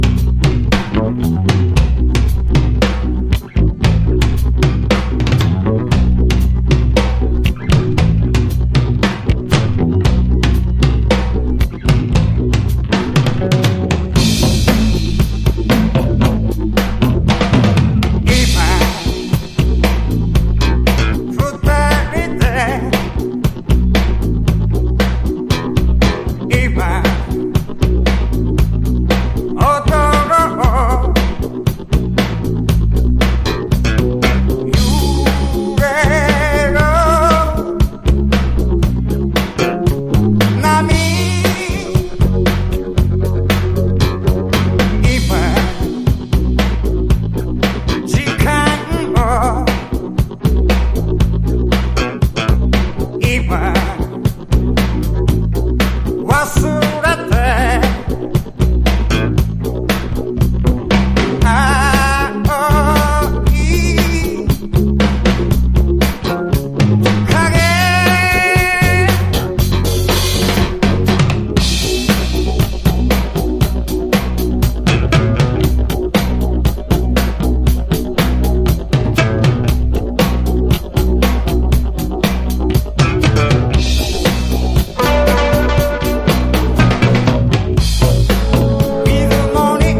独自のニューウェーブ路線を走ってます。
ボーカルも個性立ちまくりです。
和モノ / ポピュラー